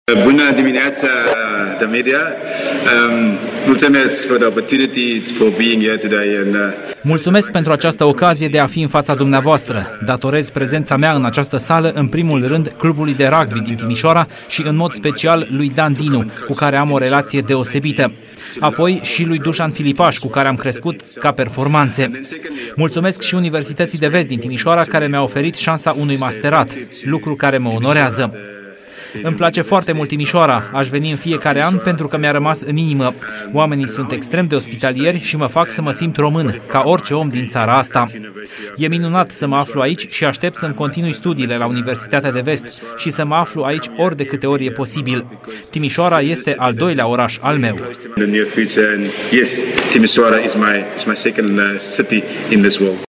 Fostul antrenor al echipei de rugby RCM Timișoara, sud-africanul Chester Williams, a fost distins astăzi cu titlul de Cetățean de Onoare al Municipiului Timișoara. Tehnicianul a primit în această dimineață distincția din partea primarului Nicolae Robu, în cadrul unei conferințe de presă.